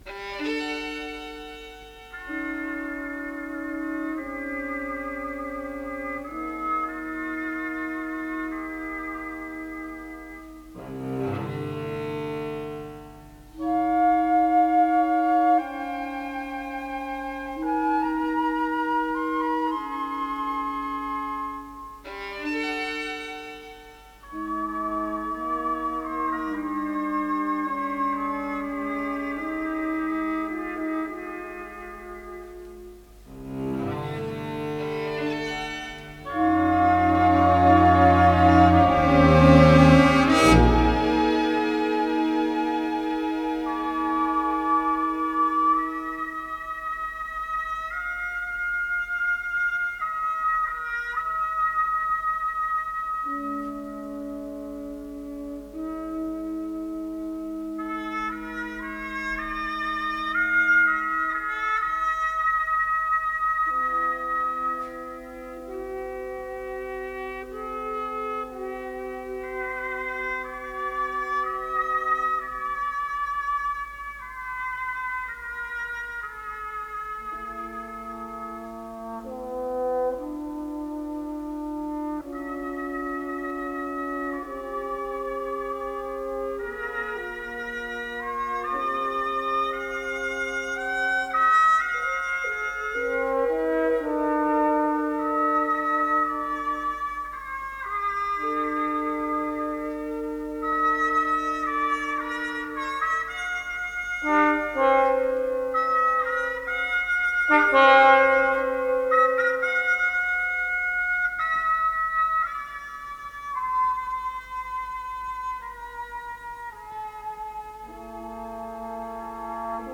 с профессиональной магнитной ленты
ПодзаголовокСюита, лёгкая музыка для малого оркестра